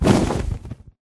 Sound / Minecraft / mob / enderdragon / wings6.ogg
wings6.ogg